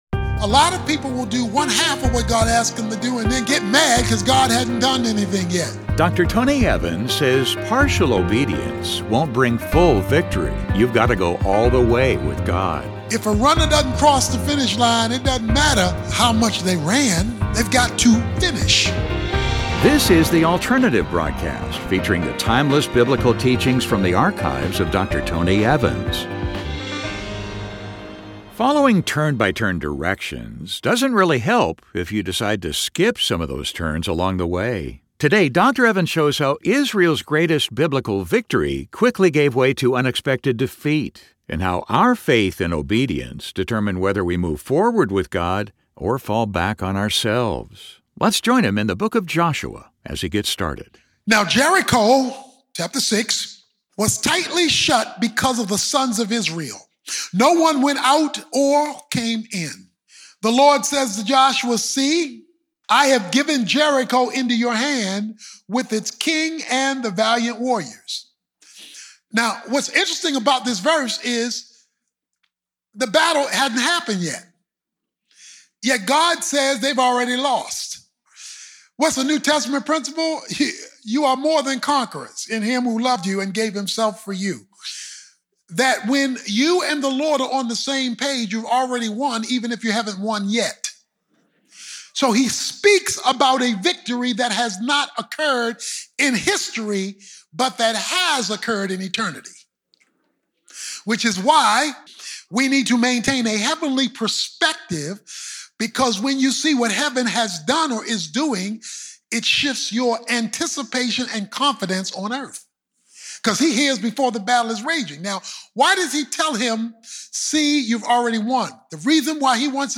Turn-by-turn directions only help if you actually follow them. In this message, Dr. Tony Evans explores how our faith and obedience determine whether we move forward with God or fall back on ourselves.